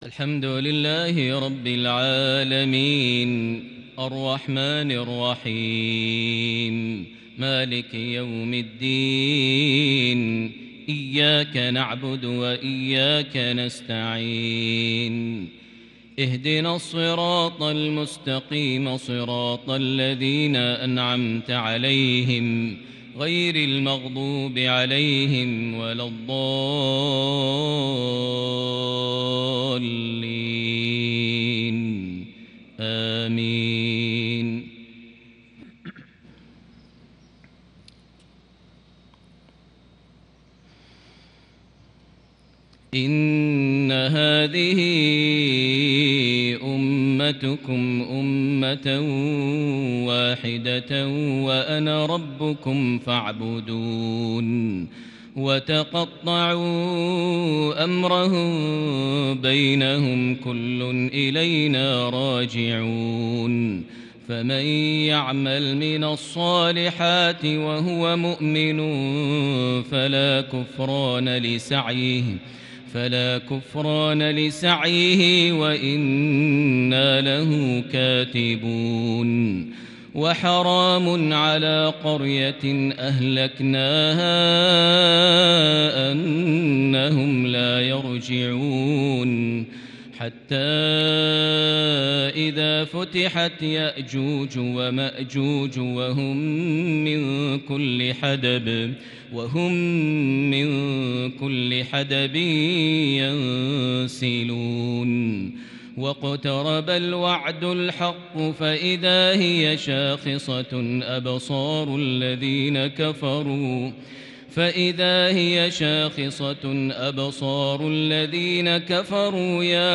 فجر الخميس 23 ذو الحجة 1441هـ خواتيم سورة الأنبياء | Fajr prayer from Surat Al-Anbiya > 1441 🕋 > الفروض - تلاوات الحرمين